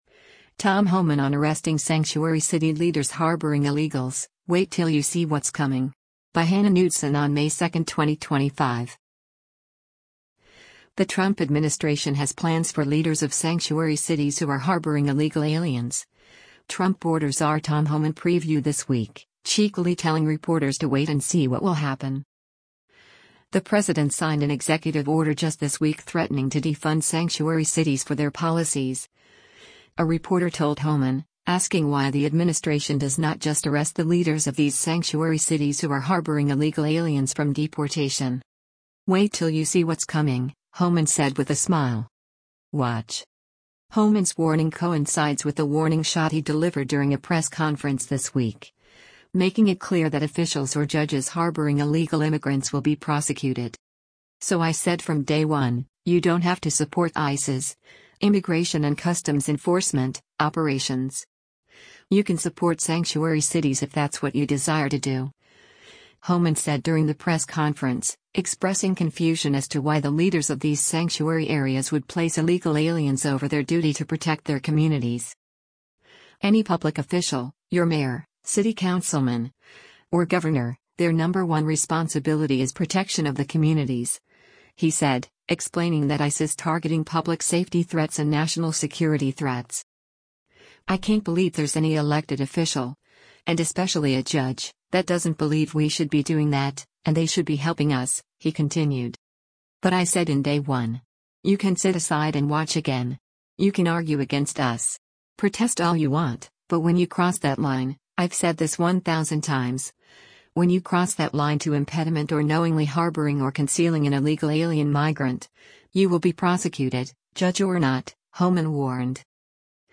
White House 'border czar' Tom Homan speaks during the daily briefing in the Brad
“Wait ‘til you see what’s coming,” Homan said with a smile.